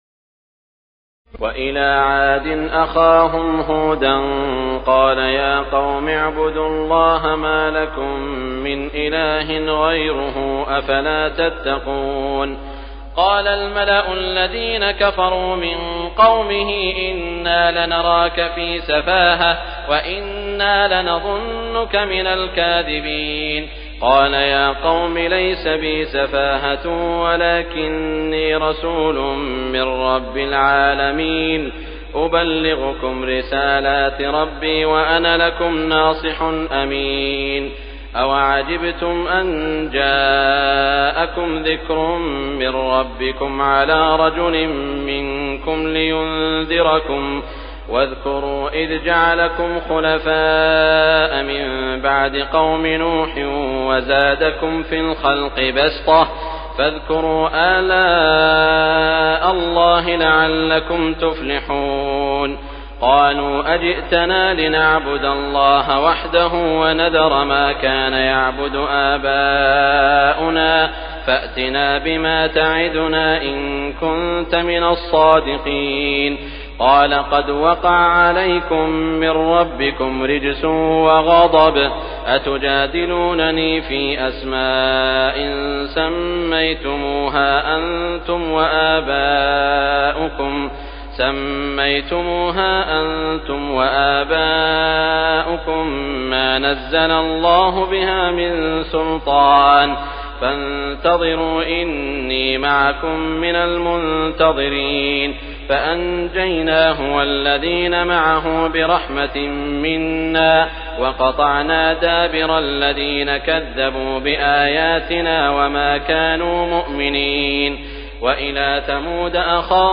تراويح الليلة التاسعة رمضان 1418هـ من سورة الأعراف (65-162) Taraweeh 9 st night Ramadan 1418H from Surah Al-A’raf > تراويح الحرم المكي عام 1418 🕋 > التراويح - تلاوات الحرمين